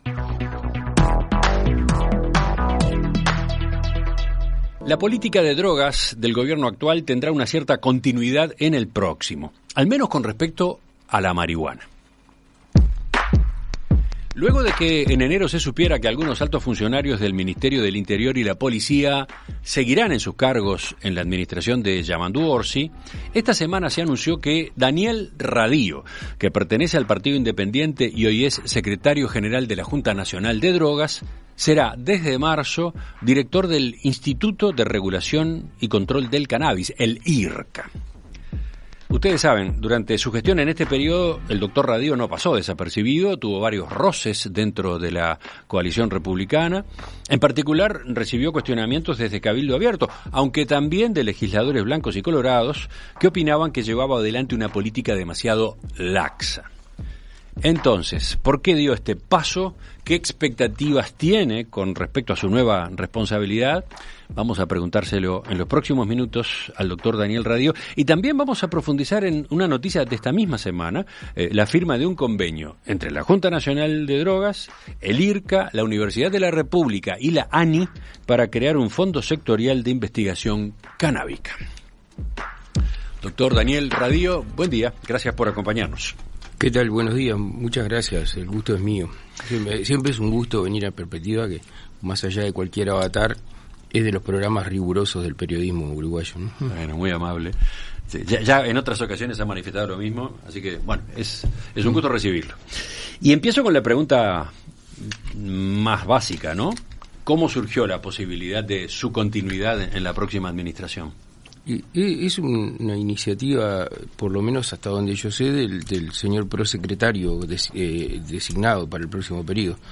En Perspectiva Zona 1 – Entrevista Central: Daniel Radío - Océano